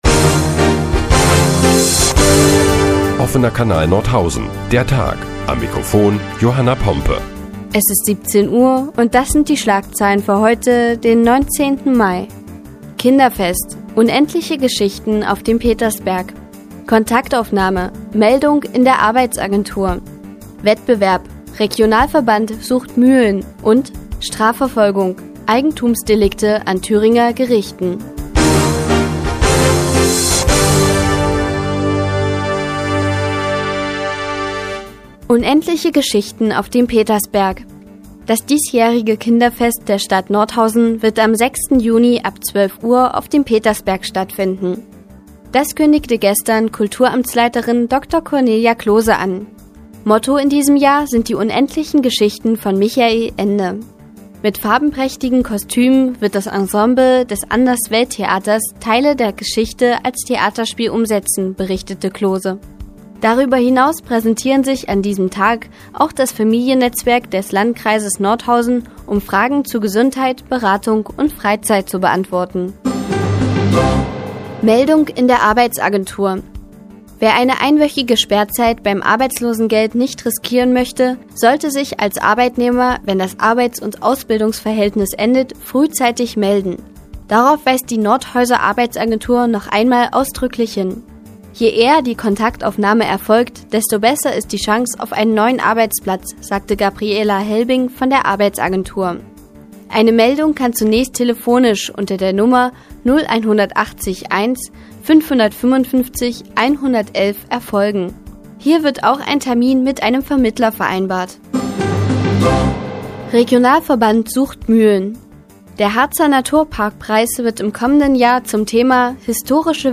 Die tägliche Nachrichtensendung des OKN ist nun auch in der nnz zu hören. Heute geht es unter anderem um die unendliche Geschichte auf dem Petersberg und den Regionalverband auf der Suche nach Mühlen.